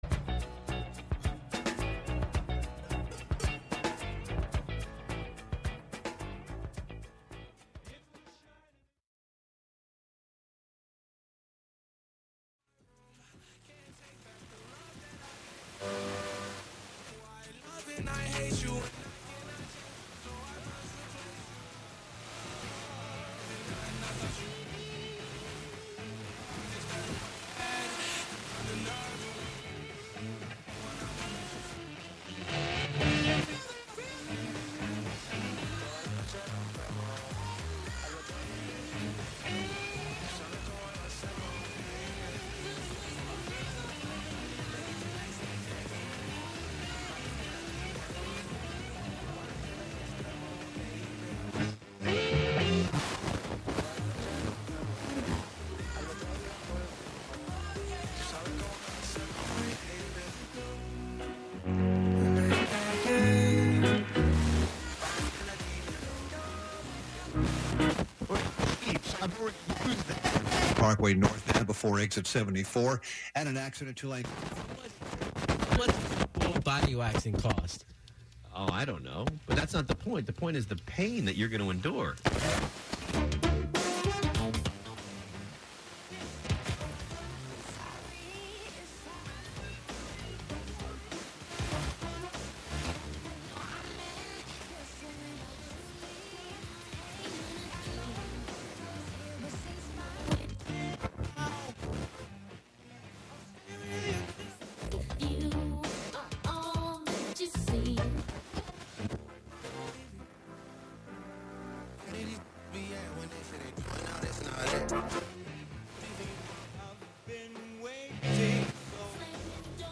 11am Live from Brooklyn, New York
making instant techno 90% of the time